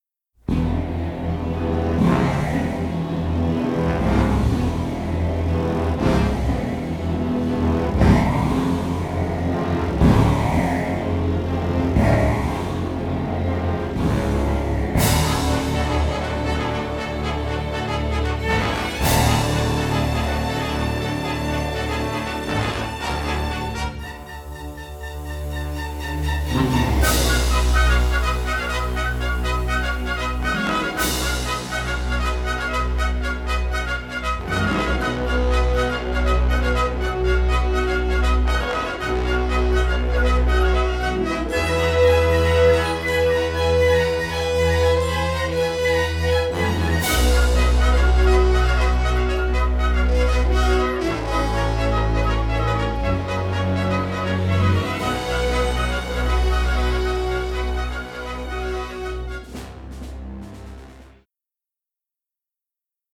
all in splendid stereo sound.
orchestra